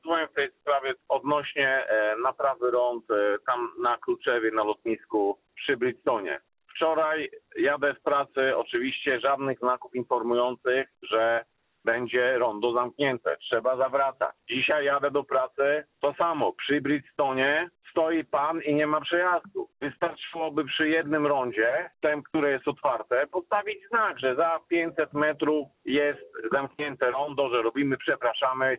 kierowca.mp3